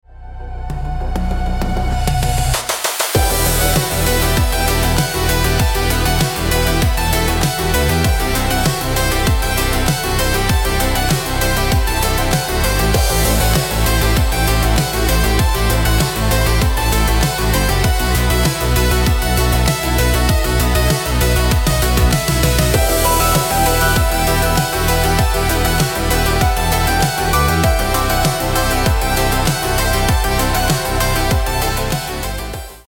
Electronic / Synth
Modern electronic pulses and synth-driven cinematic rhythm